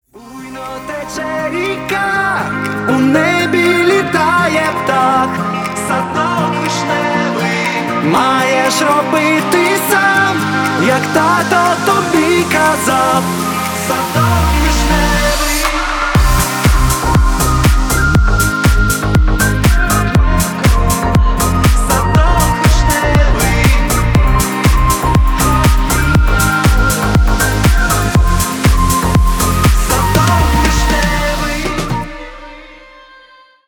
поп
громкие
deep house